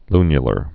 (lnyə-lər)